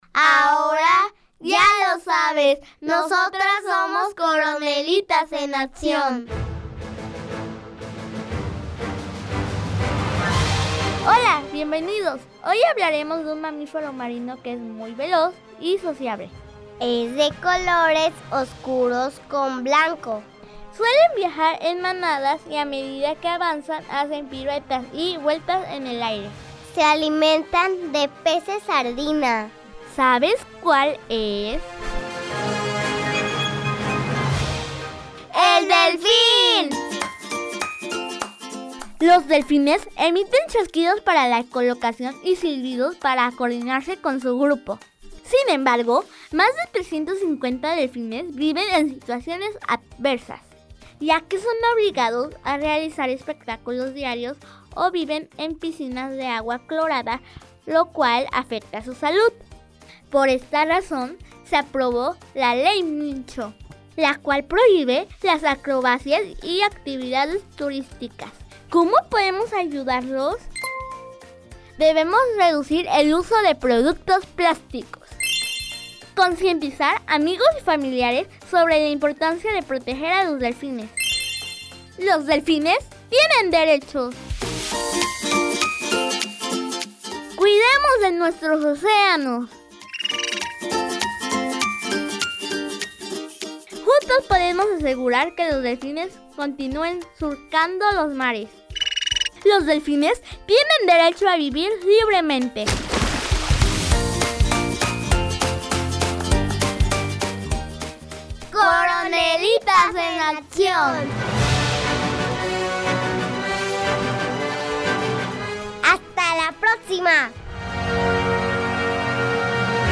Son cápsulas radiofónicas que se encargan de hacer reflexionar acerca de los derechos de los animales, enfocándose en el conocimiento de especies en peligro de extinción o vulnerables, y haciendo referencia a la Declaración Universal de los Derechos de los Animales.